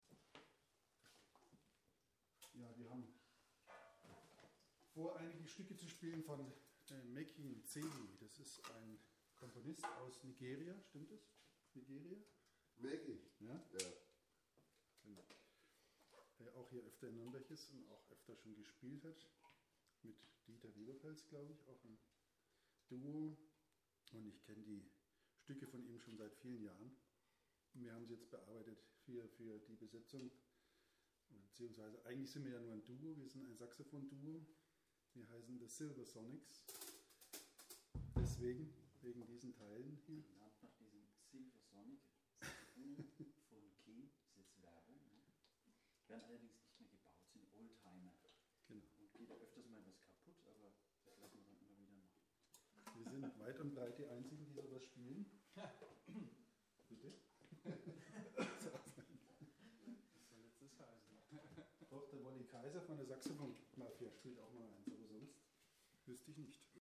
Live-Mitschnitt vom 5. April 2000
01a ansage
01a_ansage.mp3